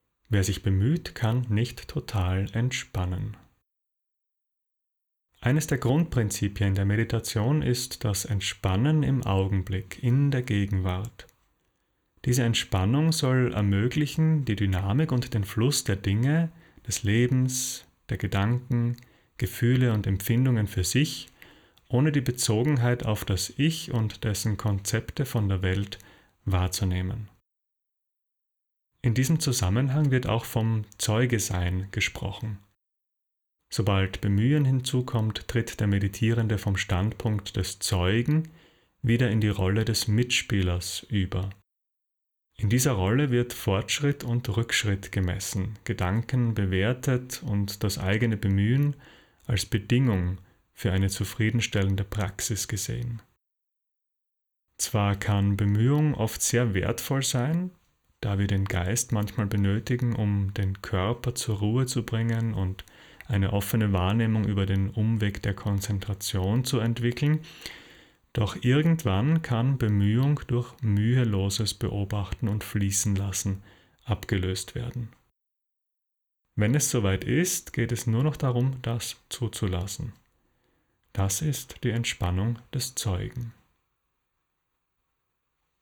Text zum Hören (01:25 Min.):